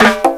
TIMB+PERC1-R.wav